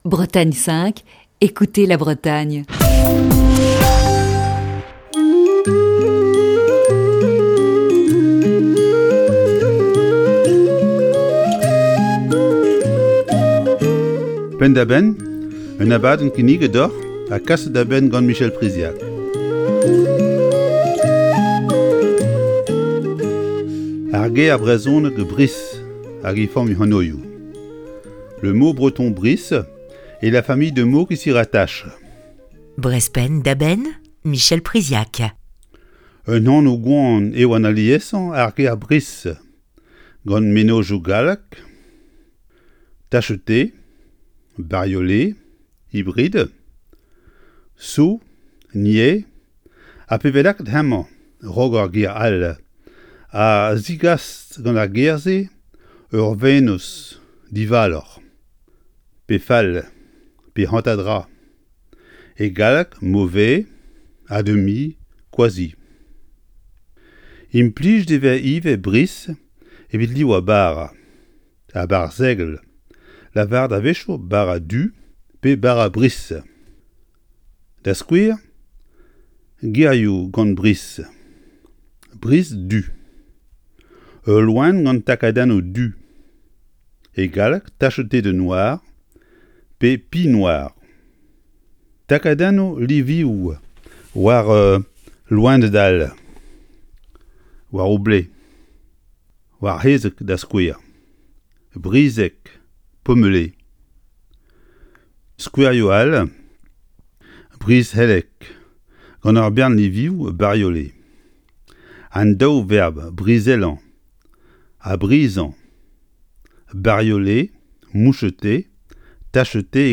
Aujourd'hui, c'est cours de breton dans Breizh Penn da Benn.